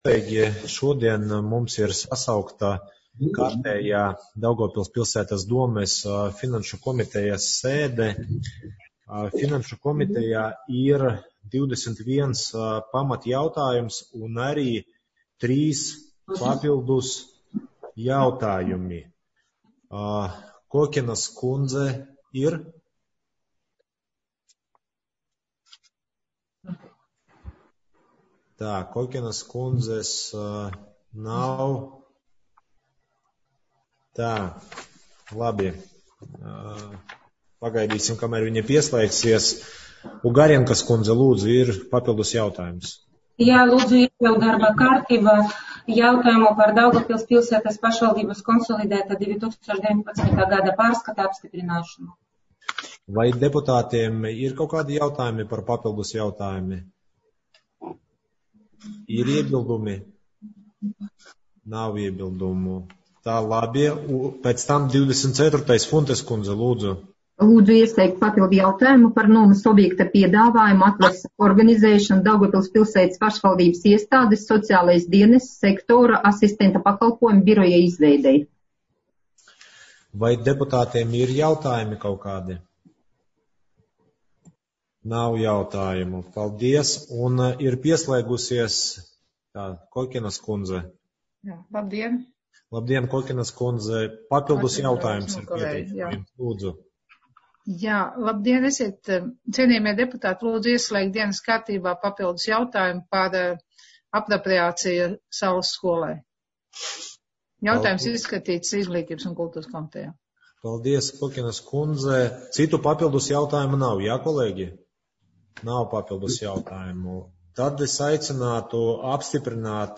Videokonferences režīmā (AUDIO)